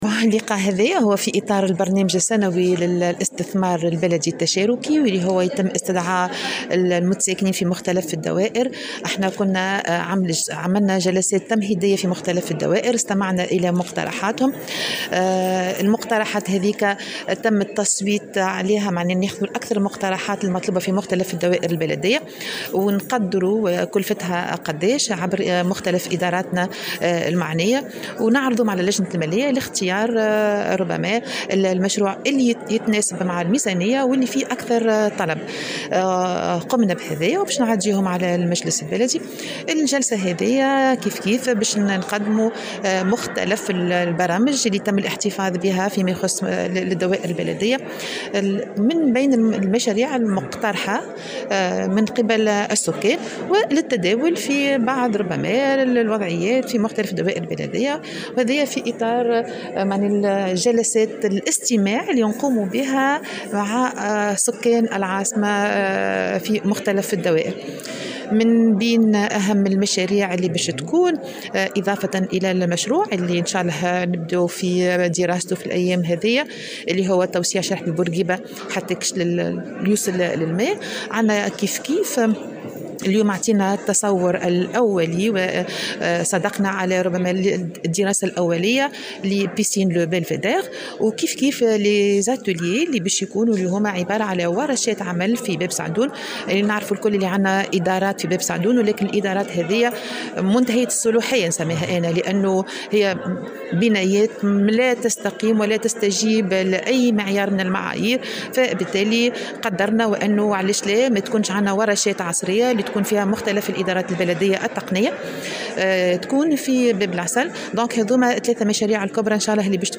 أعلنت سعاد عبد الرحيم، رئيسة بلدية تونس، في تصريح لمراسل الجوهرة أف أم، عن مشروع...